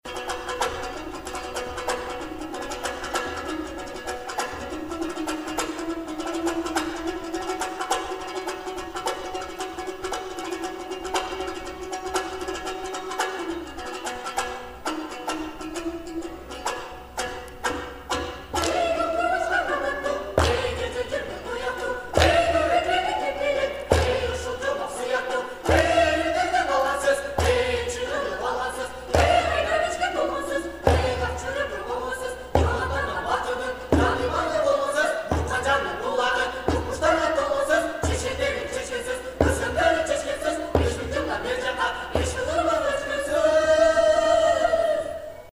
Комуз